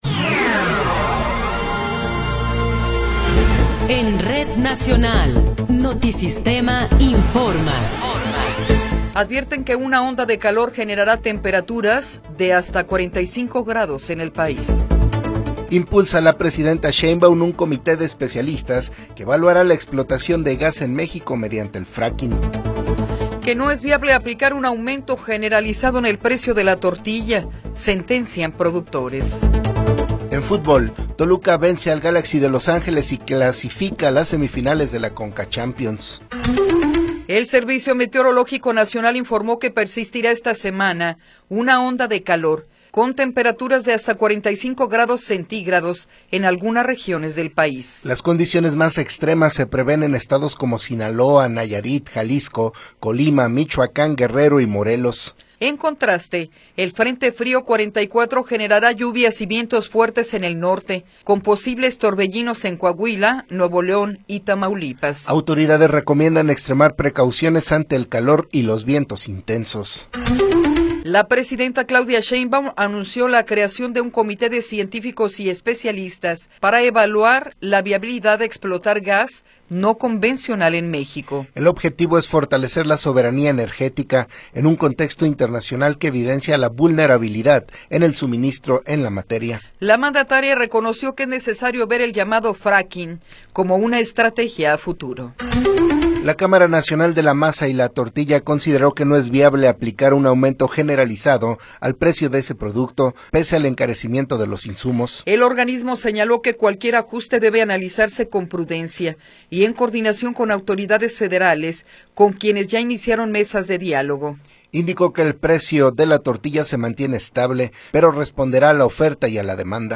Noticiero 8 hrs. – 16 de Abril de 2026